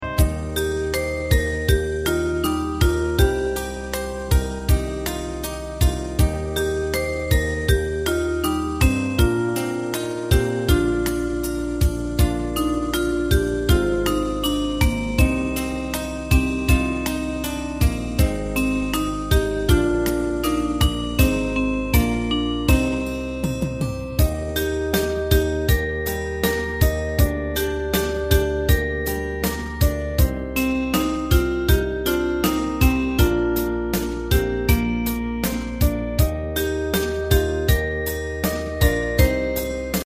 Ensemble musical score and practice for data.